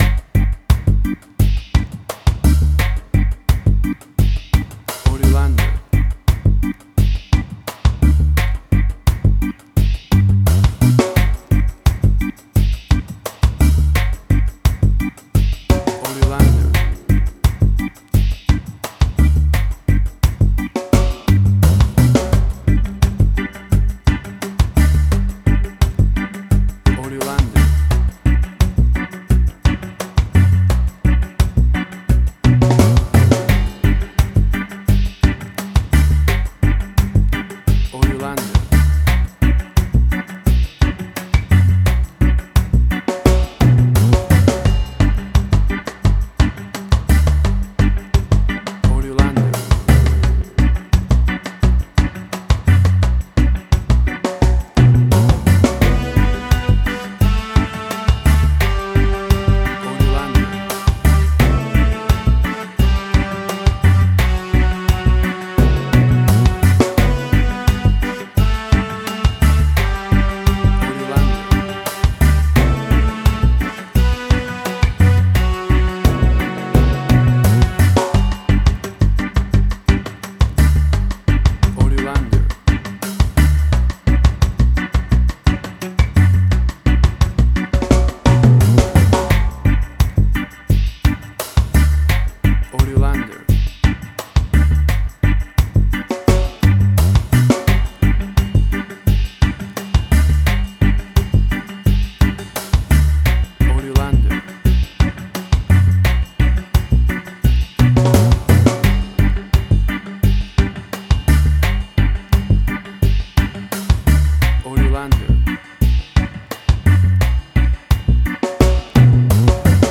Reggae caribbean Dub Roots
Tempo (BPM): 86